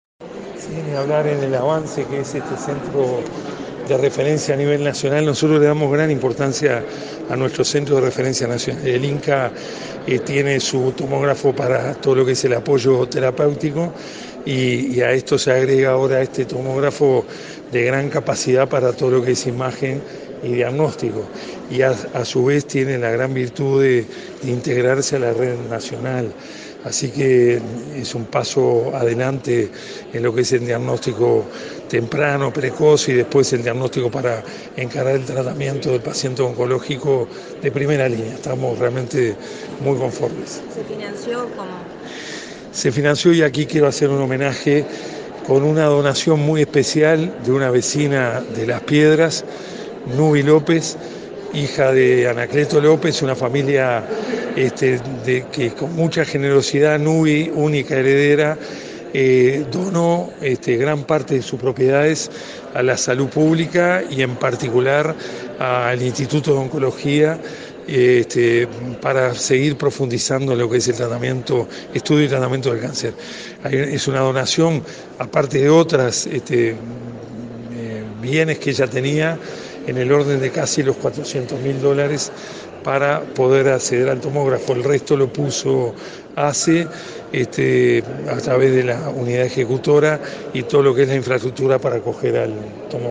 El presidente de ASSE, Marcos Carámbula, sostuvo que el nuevo tomógrafo, de gran capacidad para imagen y diagnóstico, adquirido para el Instituto Nacional del Cáncer, es un paso adelante para el diagnóstico temprano y precoz de patologías oncológicas. La nueva tecnología fue adquirida tras una donación de un particular al Instituto del Cáncer por 400.000 dólares.